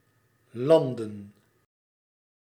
Ääntäminen
IPA: /ˈlɑn.də(n)/